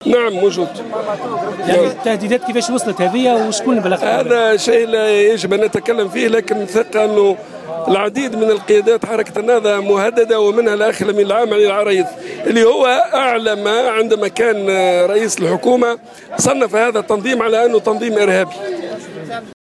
أكد القيادي في حزب حركة النهضة،عبد اللطيف المكي اليوم في تصريح "لجوهرة أف أم" وجود تهديدات جدّية تستهدف الأمين العام للحركة علي العريض.
وأضاف عبد اللطيف المكي خلال المسيرة التي نظمتها حركة النهضة اليوم للتنديد بالهجوم الإرهابي الذي استهدف عددا من عناصر الجيش الوطني بالشعانبي أن هذه التهديدات تستهدف أيضا عديد القيادات الأخرى في الحركة.